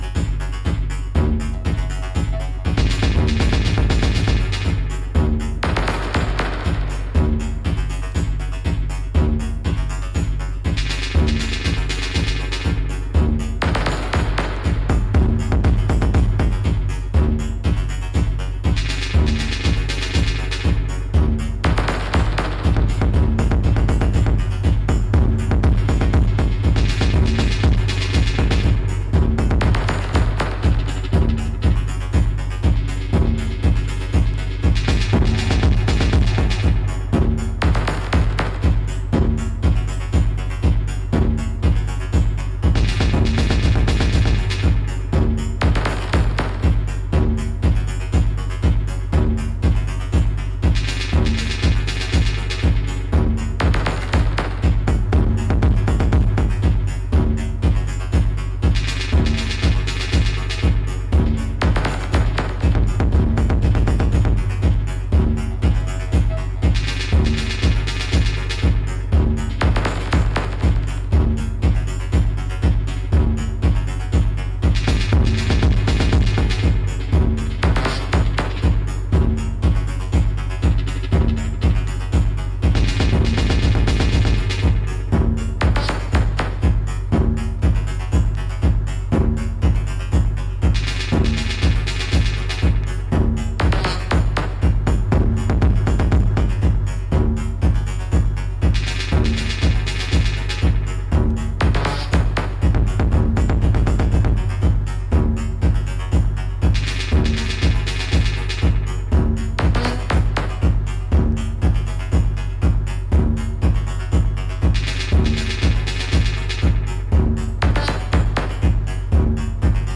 who delivers six cuts of Post Wave intentions.
broken percussive tribalism
Electro Techno Wave